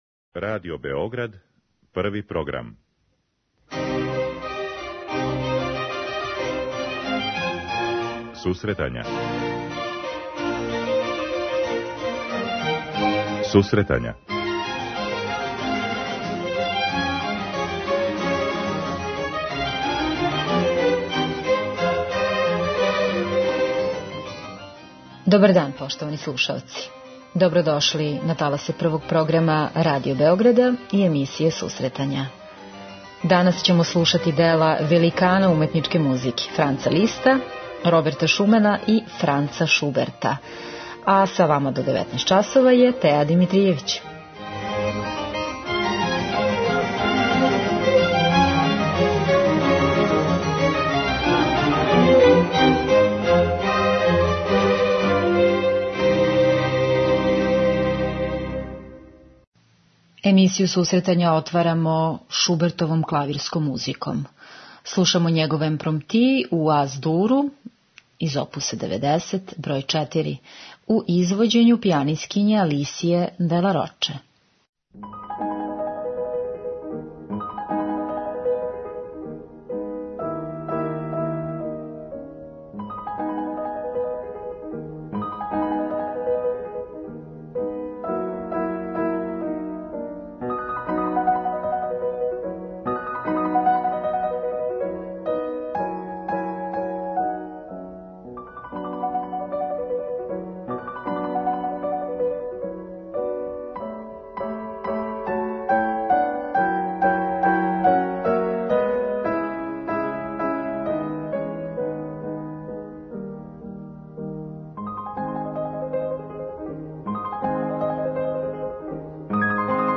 Данас уживамо у виртуозним, полетним али и сентименталним делима Франца Шуберта, Роберта Шумана и Франца Листа.
Емисија за оне који воле уметничку музику.